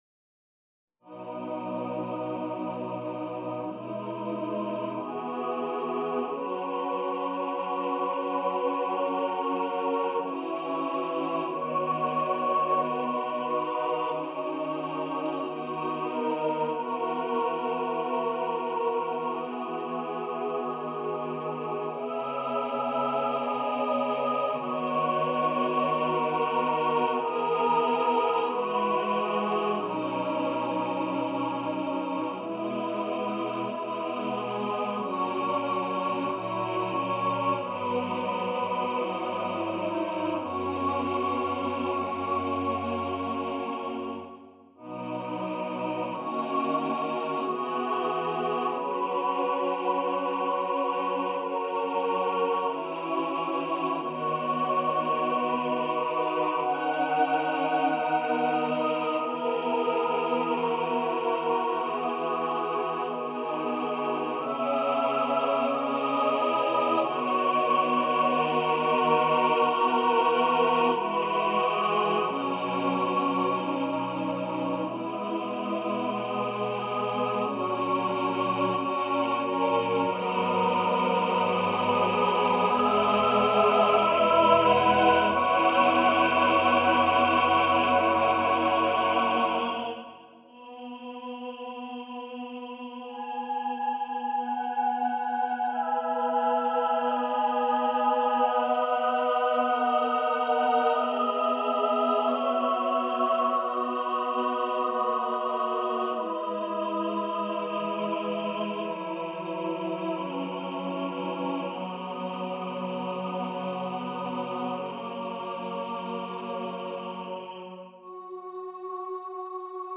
for mixed voice choir
A setting for unaccompanied mixed voice choir (SATB)